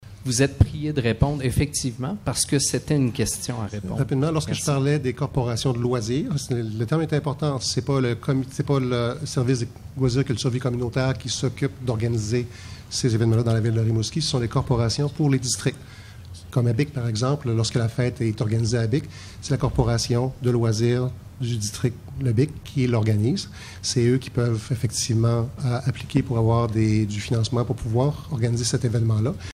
Lors d’une récente assemblée du conseil municipal